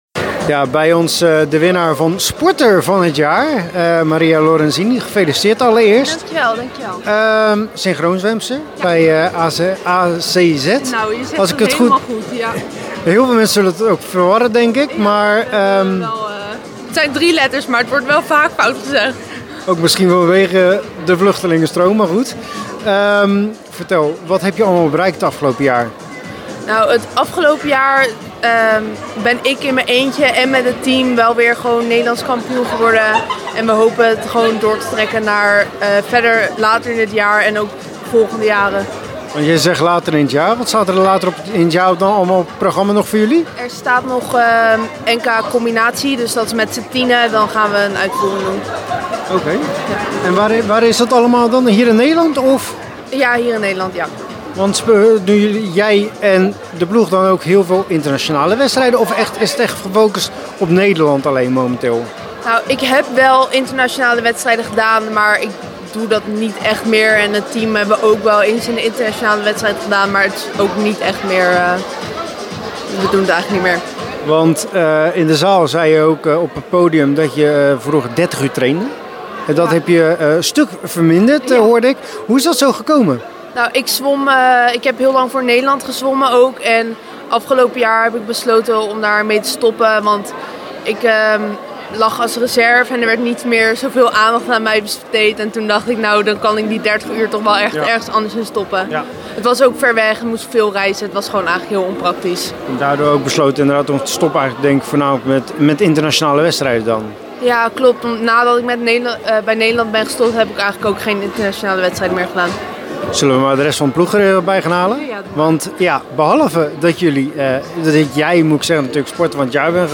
In deze podcast een interview met haar.